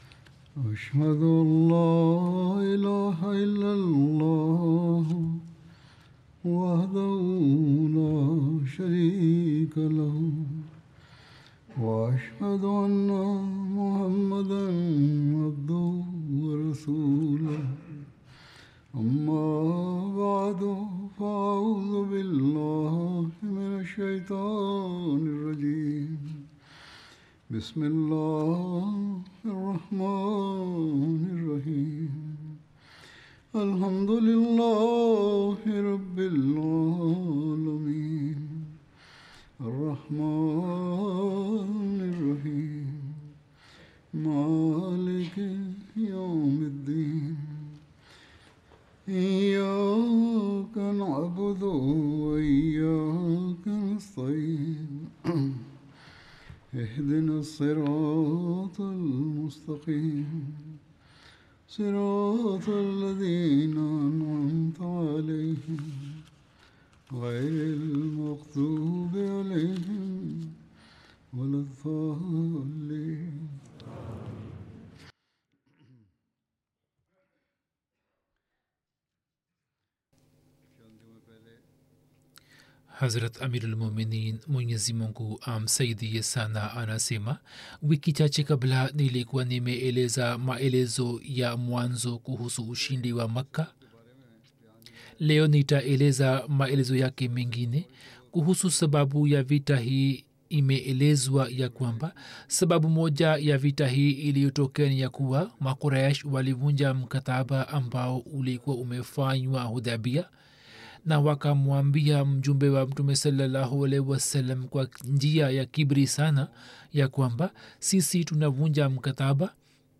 Swahili Translation of Friday Sermon delivered by Khalifatul Masih